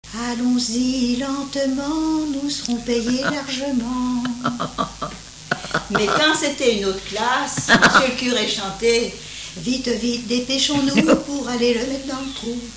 Chants brefs - Conscription
Pièce musicale inédite